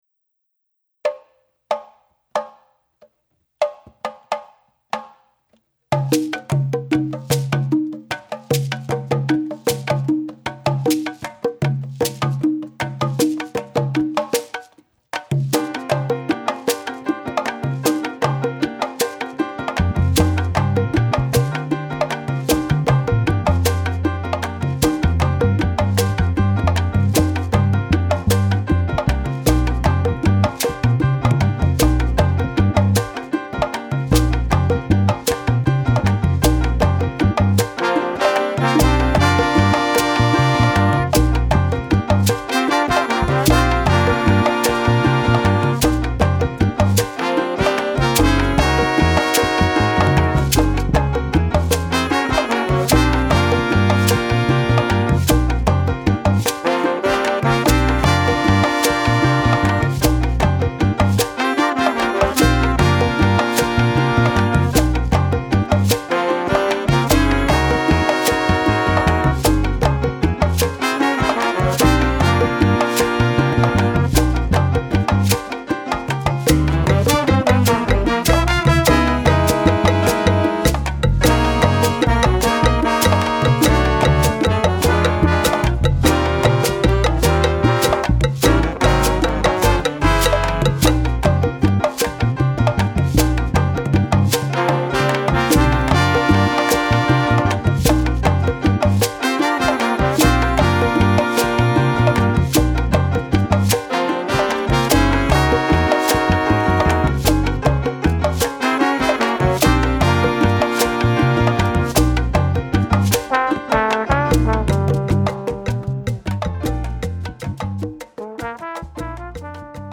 piano & tres (cuban guitar)
congas, chekere, bata
drumset, timbales, bata, shekere
bass, baby bass
electric bass
flute, tenor saxophone
trombone
trumpet, soprano saxophone